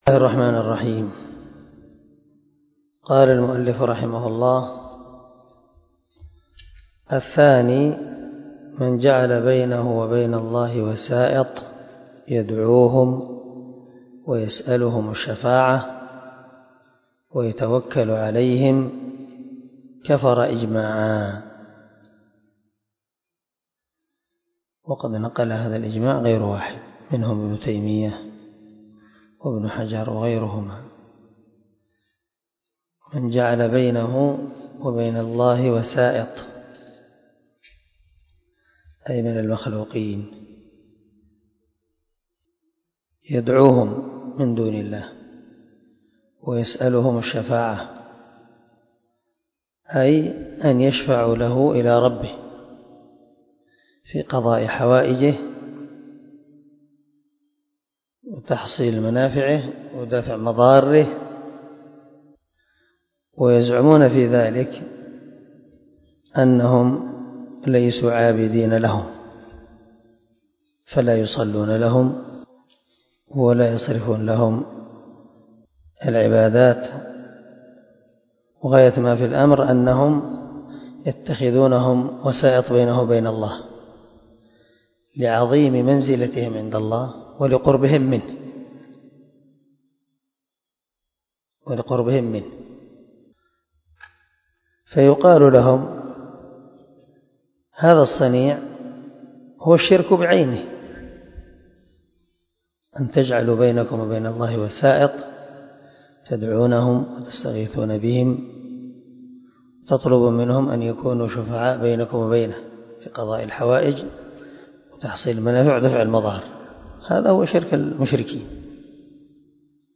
🔊الدرس 12 الناقض الثاني ( من شرح الواجبات المتحتمات)